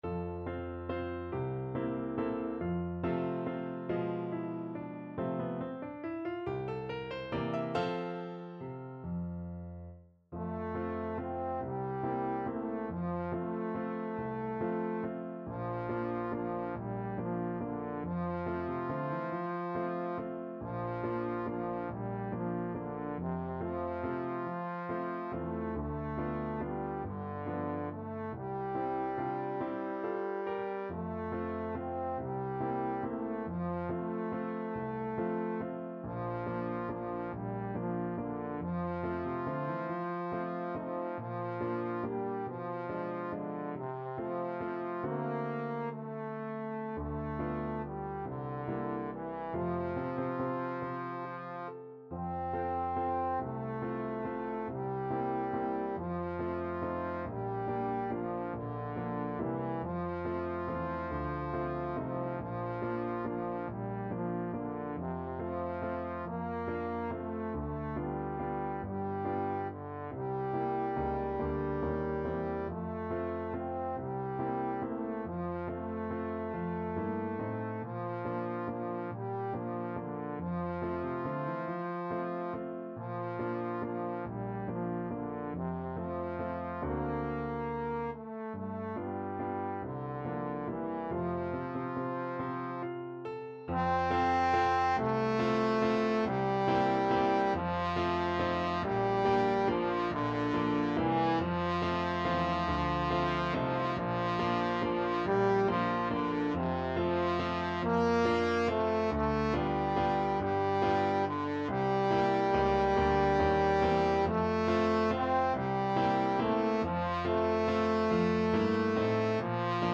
~ = 140 Tempo di Valse
3/4 (View more 3/4 Music)
C4-C5
Pop (View more Pop Trombone Music)